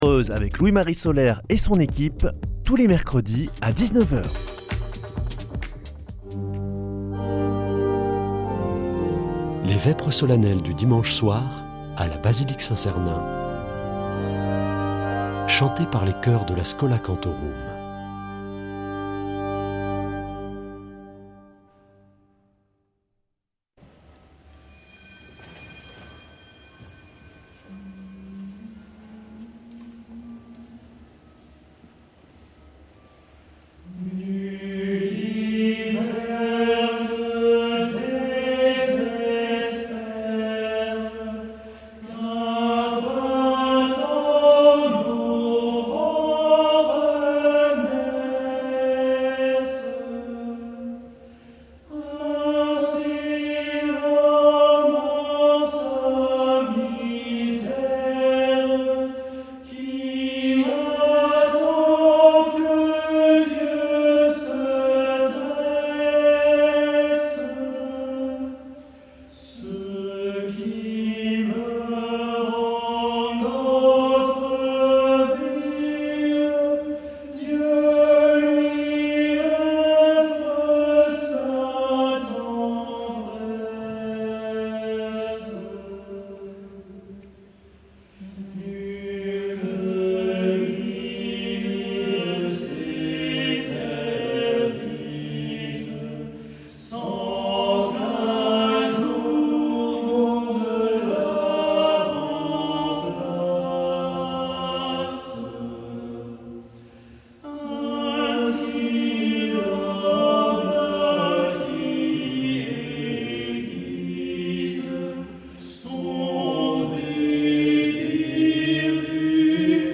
Vêpres de Saint Sernin du 25 févr.
Une émission présentée par Schola Saint Sernin Chanteurs